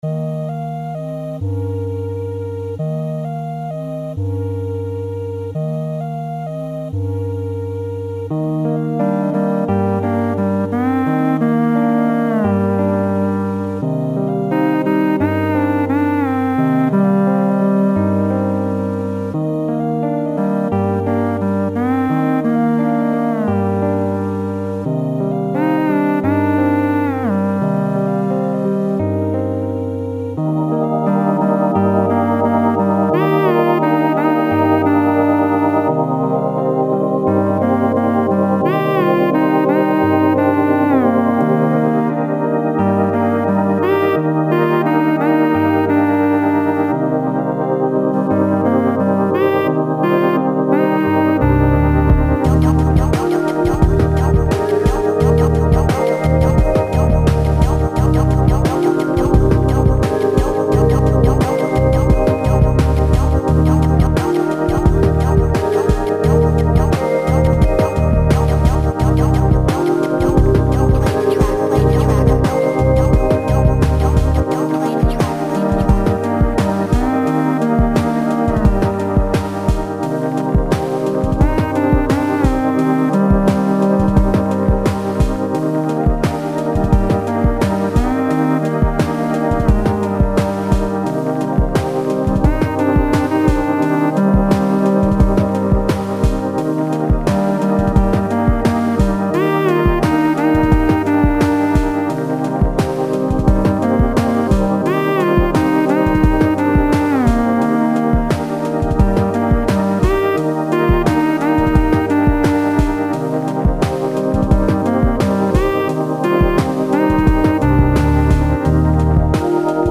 Chiptune cover
8bit 16bit